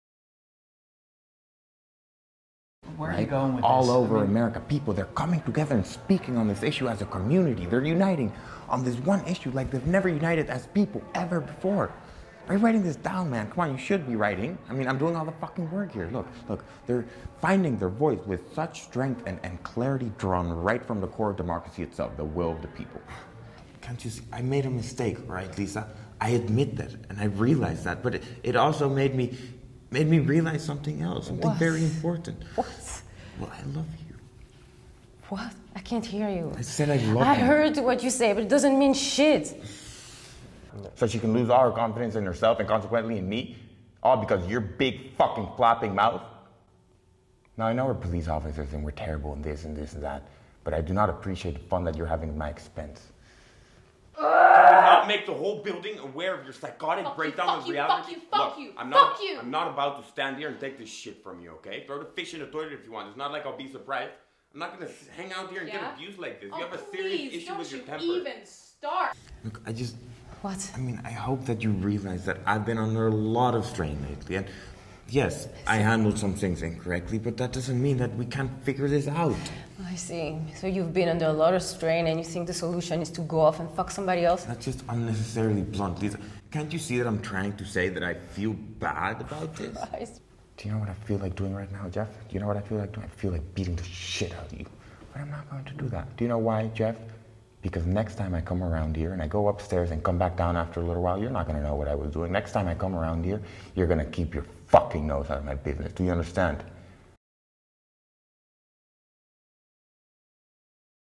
English-American
playing ages 18 to 30, male.
Voice-Reel-2020-copy.mp3